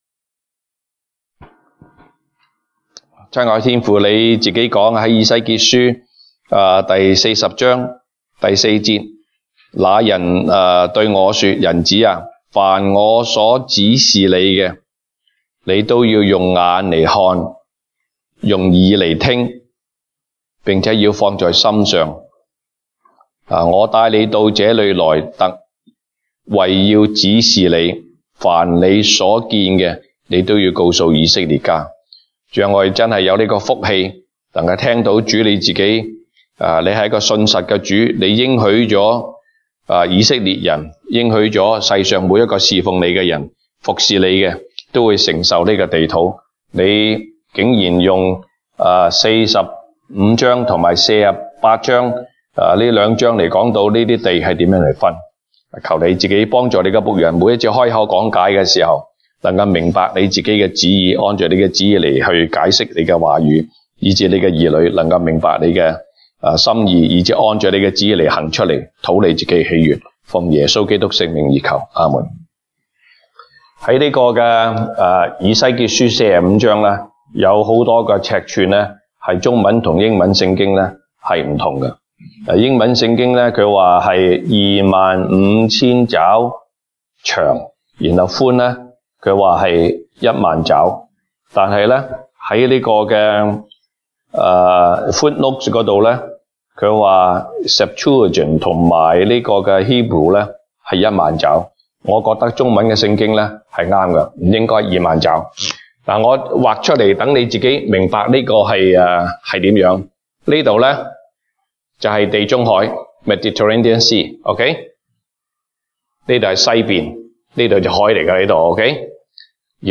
東北堂證道 (粵語) North Side: Division of the Land
Passage: 以西結書 Ezekiel 45:1-25 Service Type: 東北堂證道 (粵語) North Side (First Church)